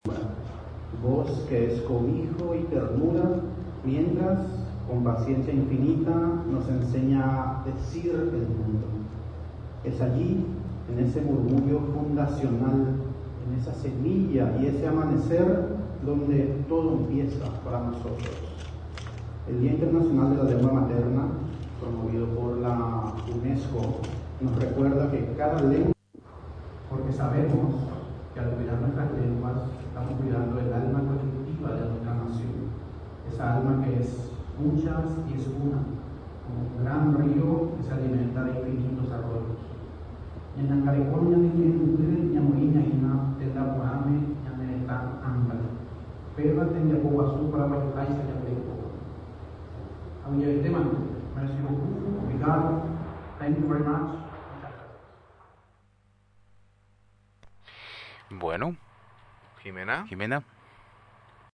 El ministro de la SPL, Javier Viveros, ofreció el discurso de apertura y bienvenida, destacando la importancia de fortalecer el bilingüismo oficial y el respeto a las lenguas maternas.